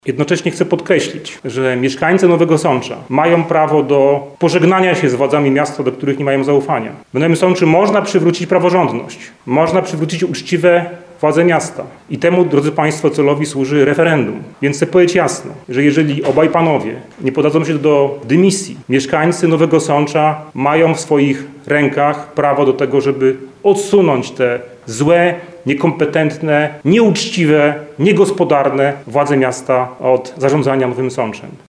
W trakcie spotkania z dziennikarzami Mularczyk podkreślał, że we współczesnej historii miasta nigdy nie doszło do takiej sytuacji. Europoseł zaapelował do prezydentów o dymisję.